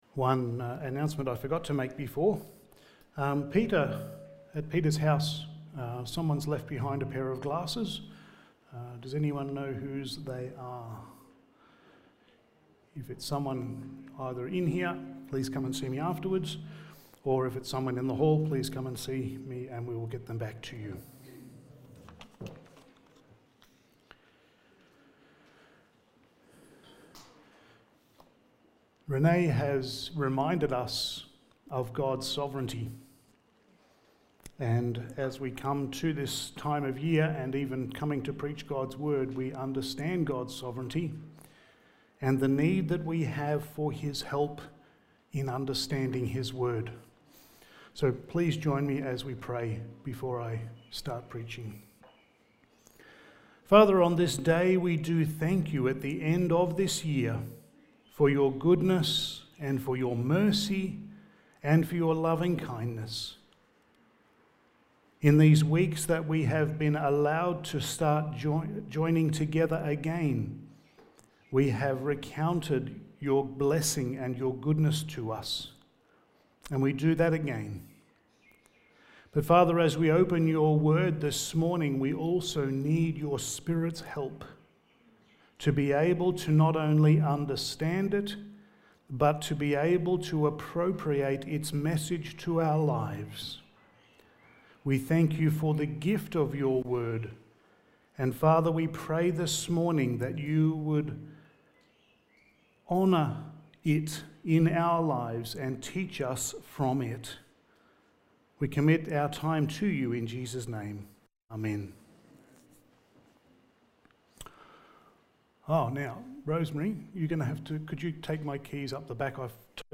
Passage: Obadiah 1:1-9 Service Type: Sunday Morning